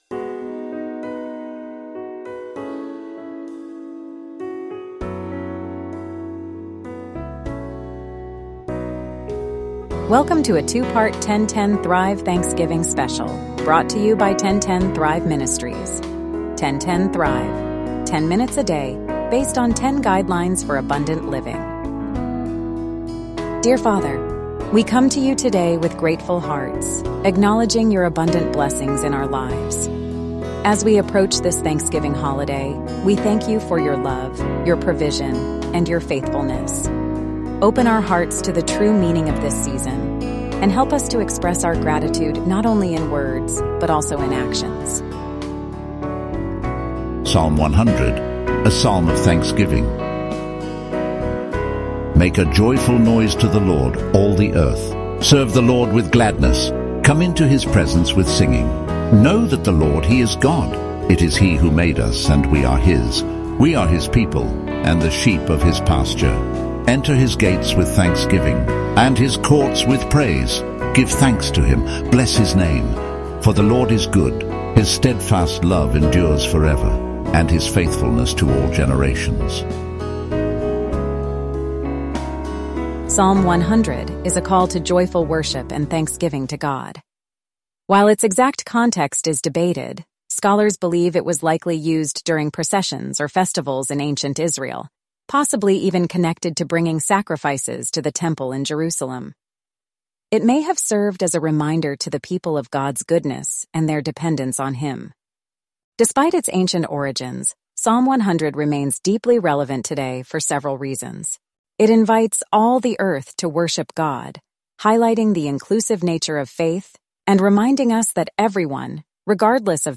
The episode also features the song "Thank You, Father," which beautifully complements the message of Psalm 100.